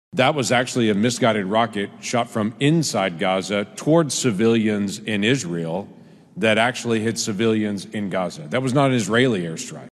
CLICK HERE to listen to commentary from James Lankford.
An Oklahoma Senator says the evidence shows an explosion that killed 500 people inside a hospital in Gaza was not caused by a rocket fired from Israel. Republican James Lankford was among several party members at a Wednesday news conference showing their support for the Jewish State.